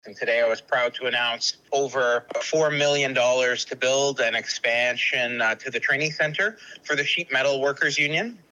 Minister of Labour, Immigration, Training and Skills Development David Piccini shares the details.